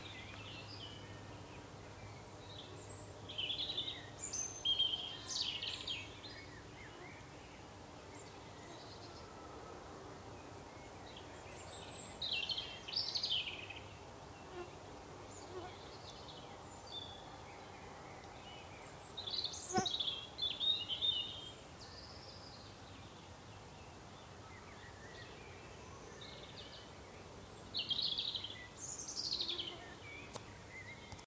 Robin (Erithacus rubecula)
Nombre en inglés: European Robin
Localización detallada: Groot Wolfswinkel
Condición: Silvestre
Certeza: Vocalización Grabada